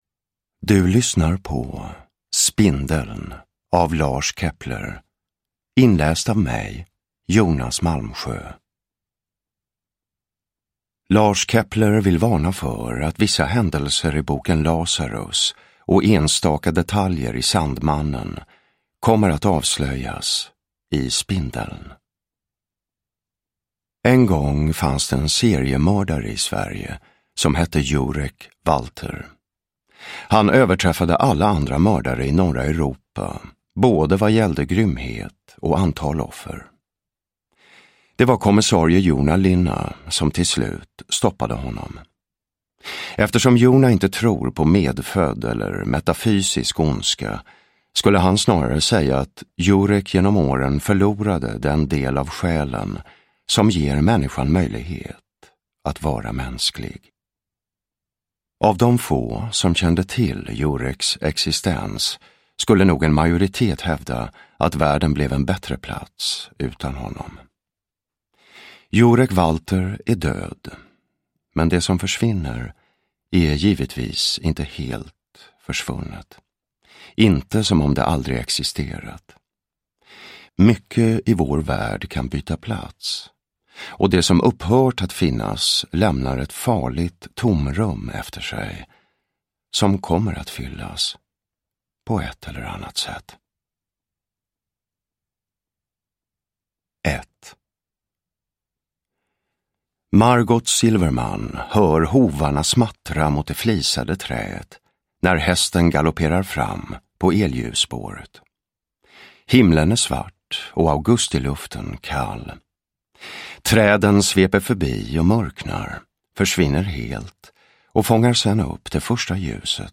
Uppläsare: Jonas Malmsjö
Ljudbok